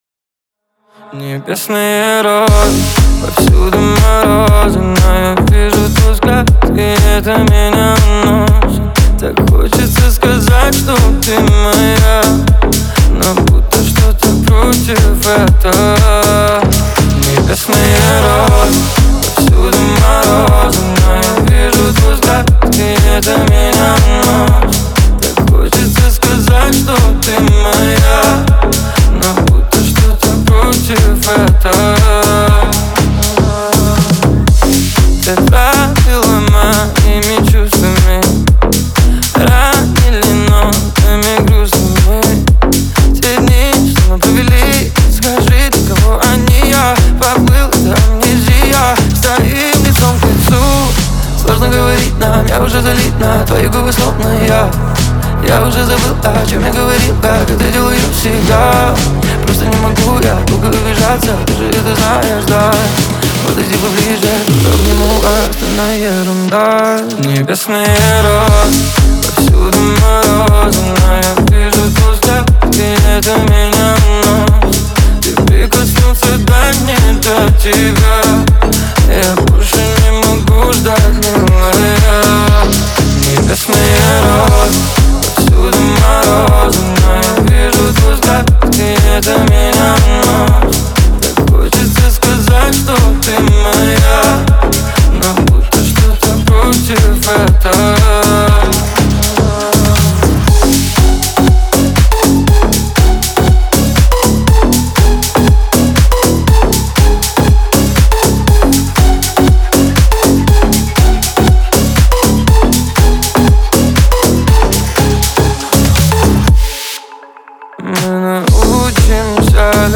Новые ремиксы